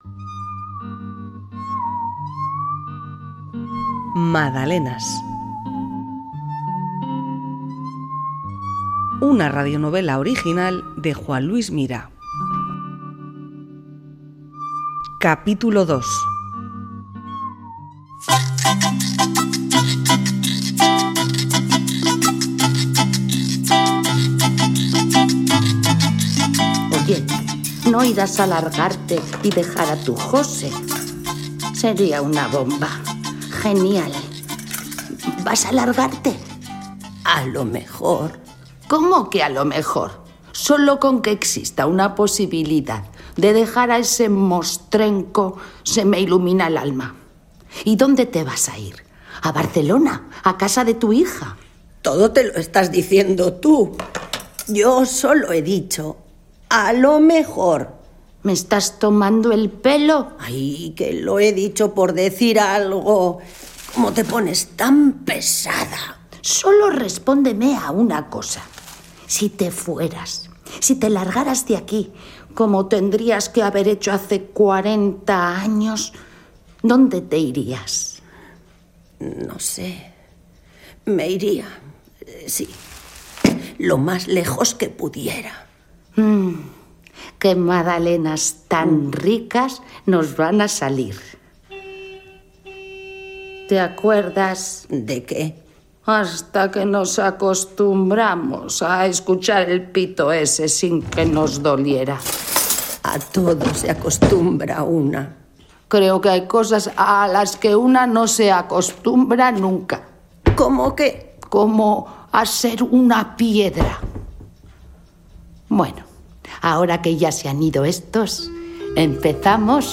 Radionovela Madalenas: Capítulo 2
Grabación y postproducción: Sonora estudios.